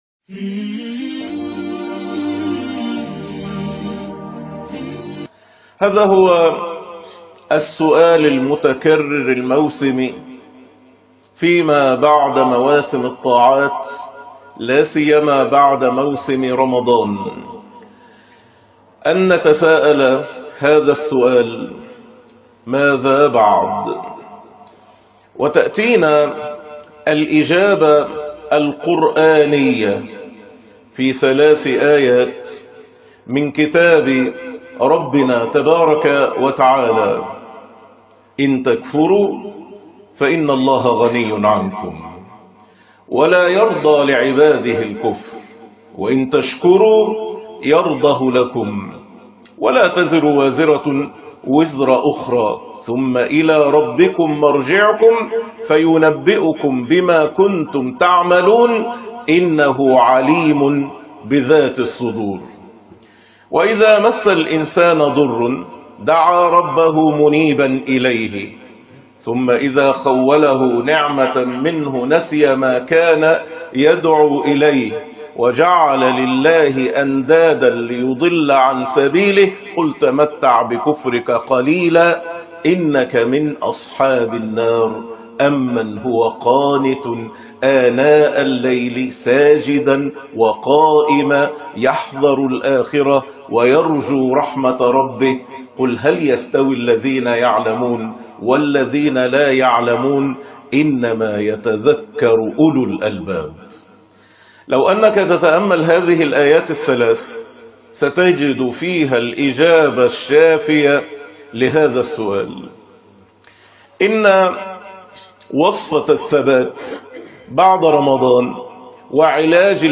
جواب القرآن عن ( ماذا بعد رمضان ) الجزء الأول ؟ خطبة مهمة وتأملات قرآنية ممتعة